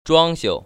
[zhuāng‧xiu] 주앙시우